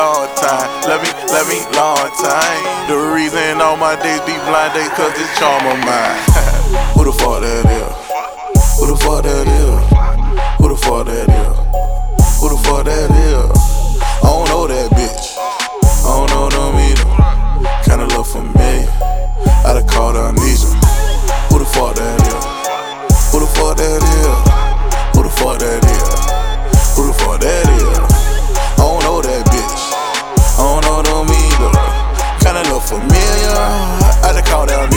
Hip-Hop Rap Rap Gangsta Rap Hardcore Rap Dirty South
Жанр: Хип-Хоп / Рэп